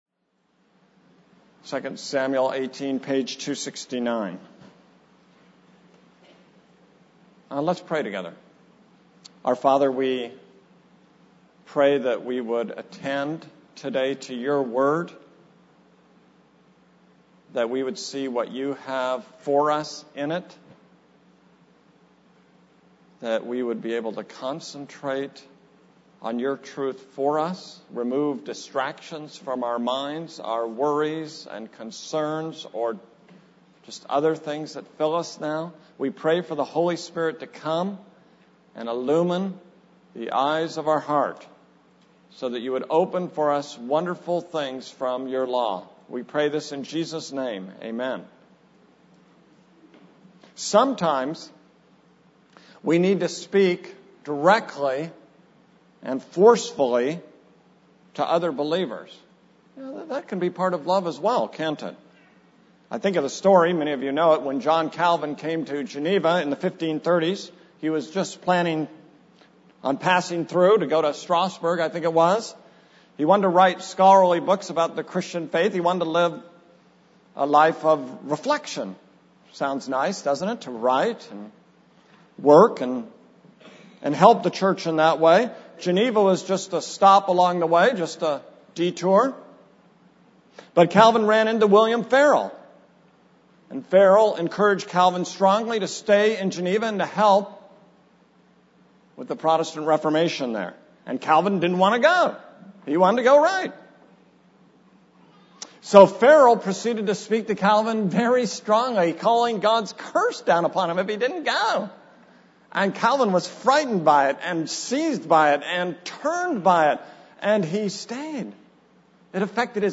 This is a sermon on 2 Samuel 18:1-19:40.